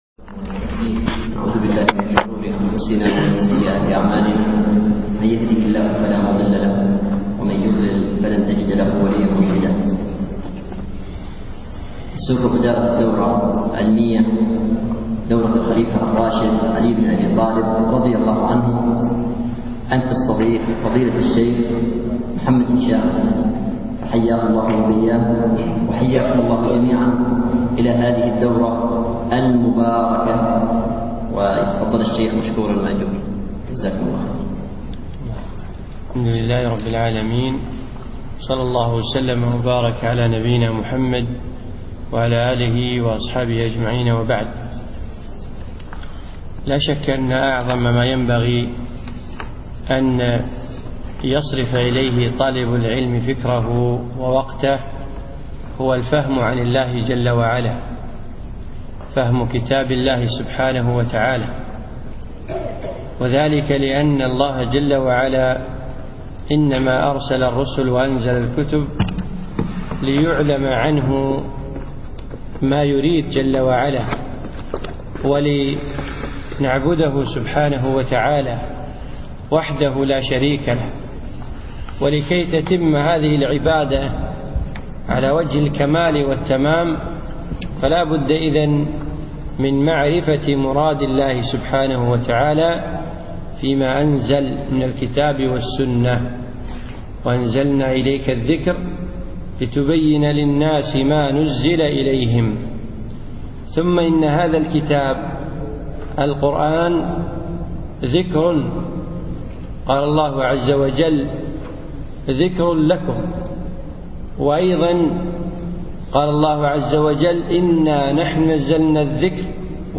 الشرح في شهر 9 عام 2011 في دورة الخليفة الراشد الخامسة
الدرس الأول